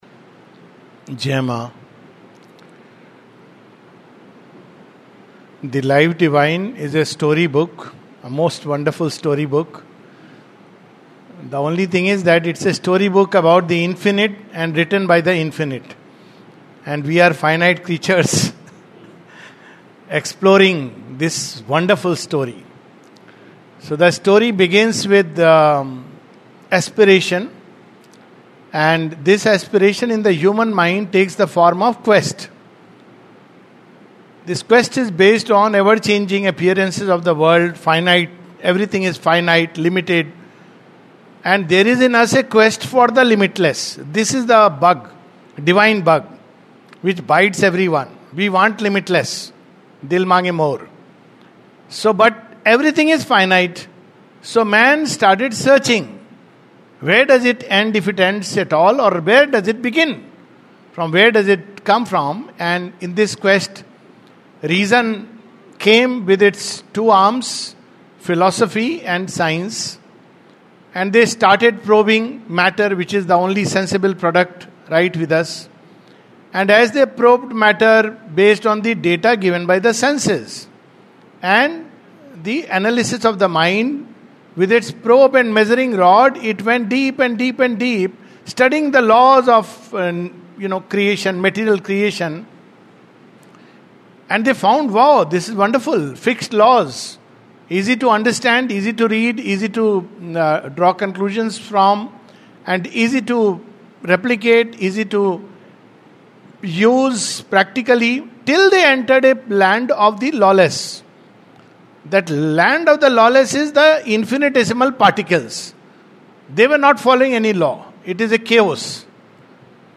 This is the concluding talk of the August 2025 Camp on The Life Divine.